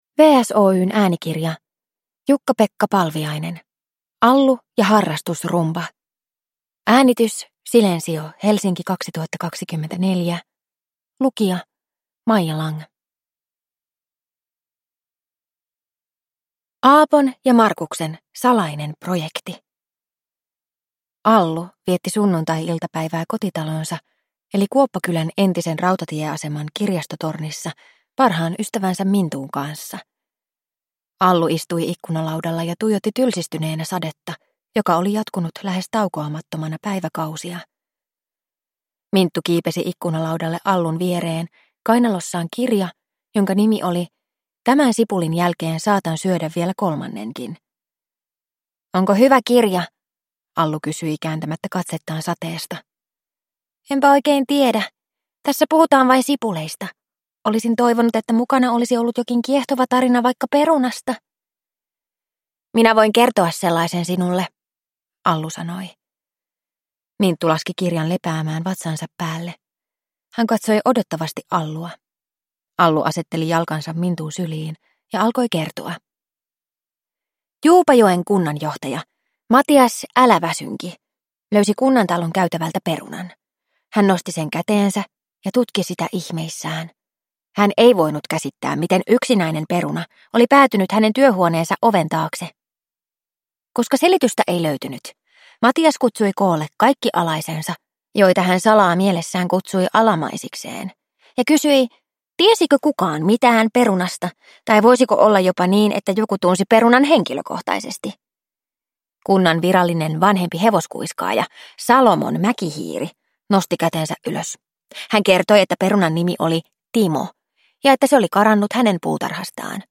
Allu ja harrastusrumba – Ljudbok